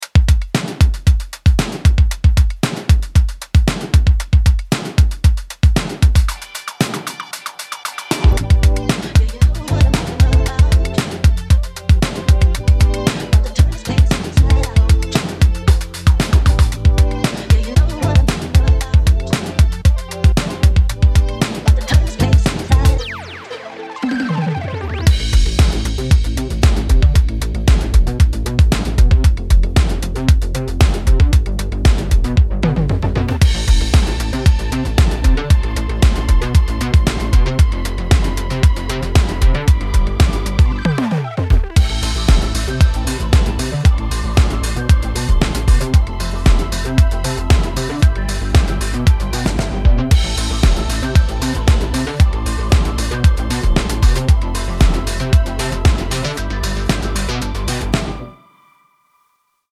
どんなトラックやミックスも、よりハードにヒットするウルトラデッド・ヴィンテージ・ドラムが魂を吹き込む